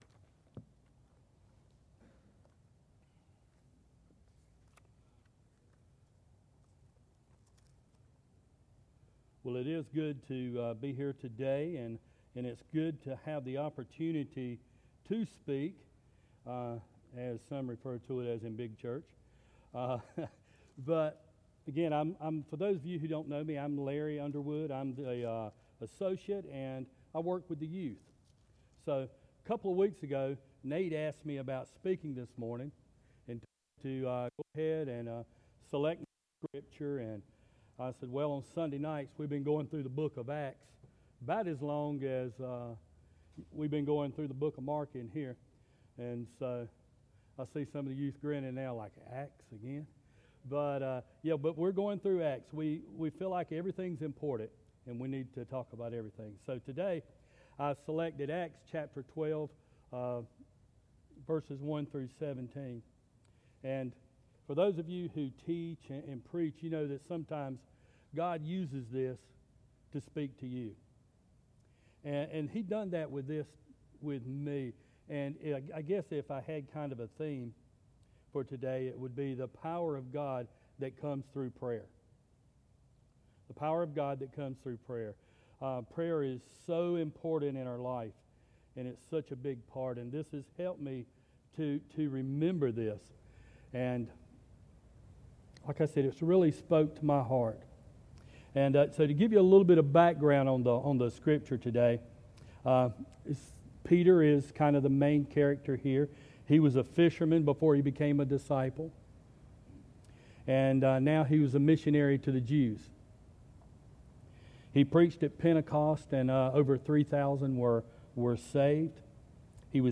Sermons - Hermon Baptist Church